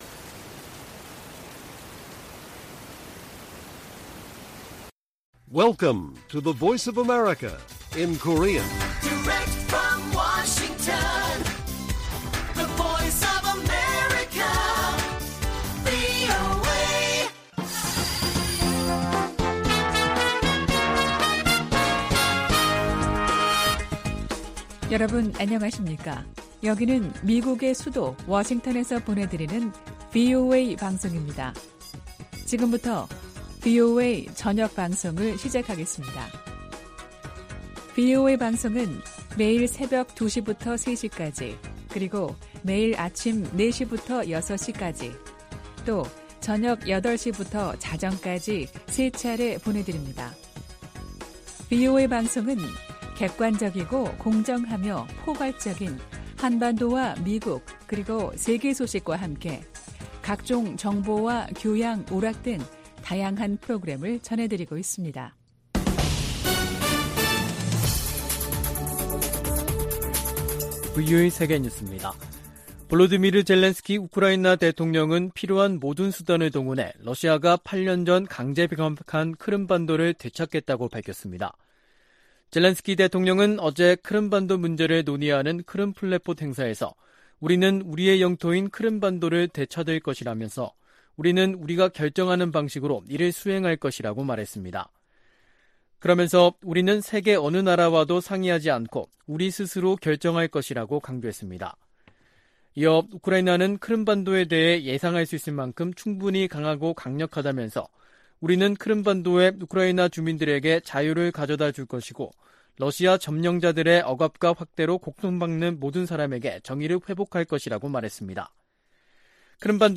VOA 한국어 간판 뉴스 프로그램 '뉴스 투데이', 2022년 8월 24일 1부 방송입니다. 제10차 핵확산금지조약(NPT) 평가회의가 한반도의 완전한 비핵화를 지지하는 내용이 포함된 최종 선언문 초안을 마련했습니다. 에드워드 마키 미 상원의원은 아시아태평양 동맹과 파트너들이 북한의 핵 프로그램 등으로 실질적 위협에 직면하고 있다고 밝혔습니다. 미국 정부가 미국인의 북한 여행 금지조치를 또다시 연장했습니다.